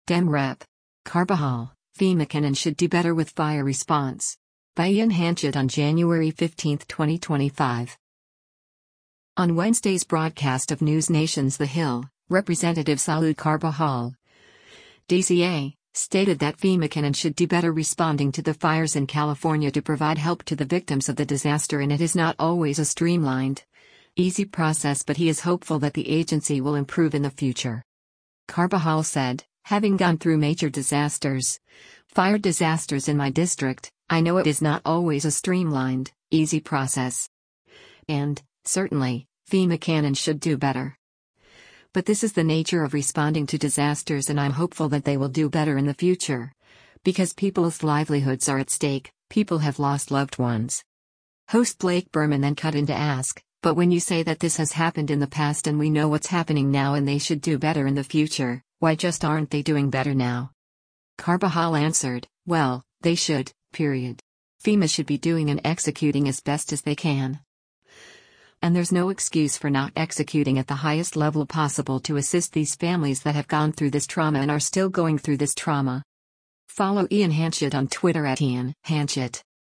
On Wednesday’s broadcast of NewsNation’s “The Hill,” Rep. Salud Carbajal (D-CA) stated that FEMA “can and should do better” responding to the fires in California to provide help to the victims of the disaster and “it is not always a streamlined, easy process” but he is “hopeful” that the agency will improve in the future.